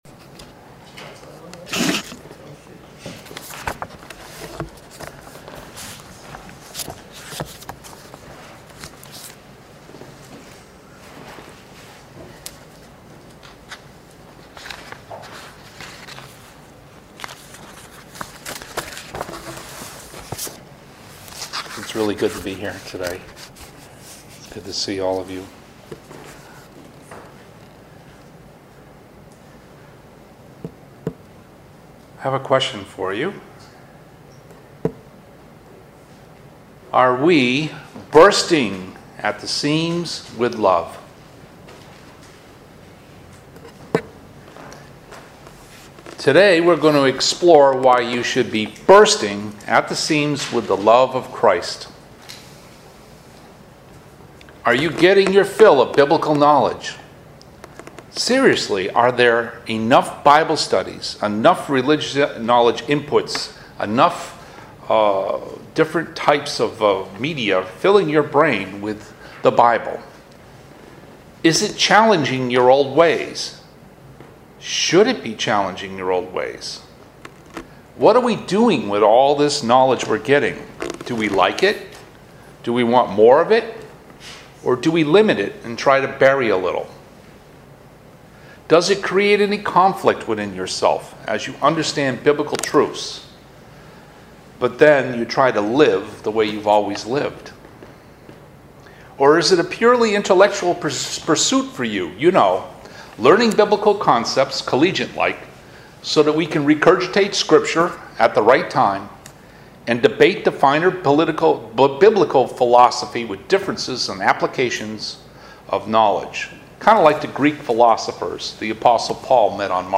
Split Sermon